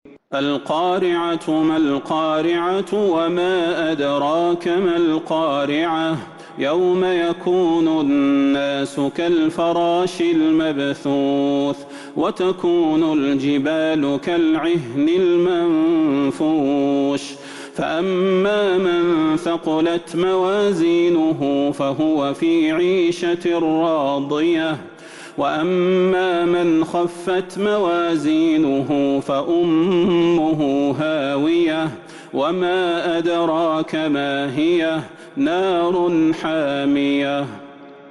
سورة القارعة Surat Al-Qari'ah من تراويح المسجد النبوي 1442هـ > مصحف تراويح الحرم النبوي عام 1442هـ > المصحف - تلاوات الحرمين